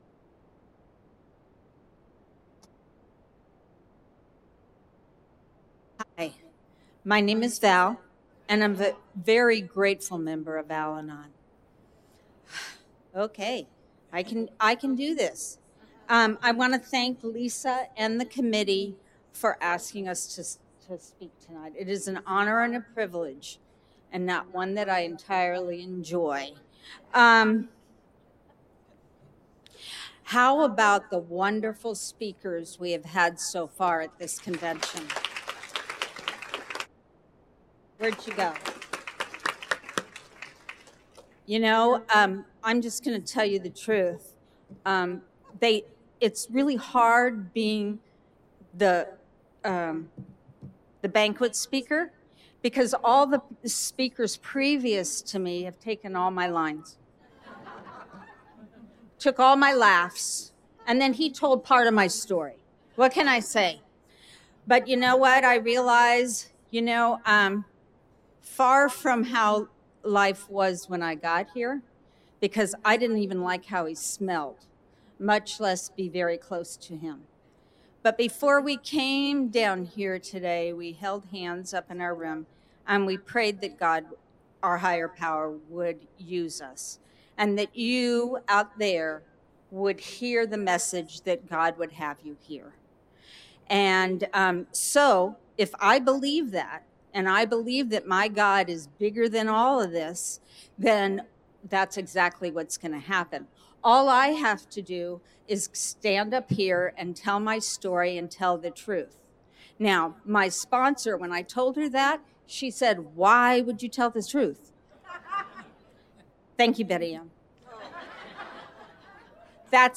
46th So Cal Al-Anon Convention